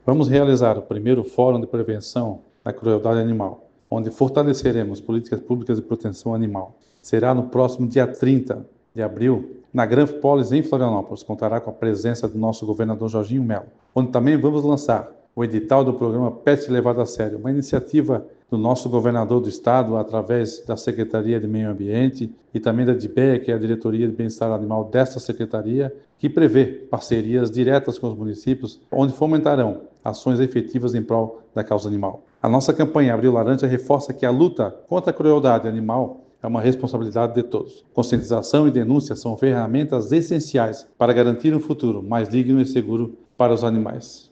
O secretário da Semae, Emerson Stein, destaca que a luta contra a crueldade animal é uma responsabilidade de todos e que a conscientização e a denúncia são ferramentas essenciais para garantir um futuro mais digno e seguro para os animais: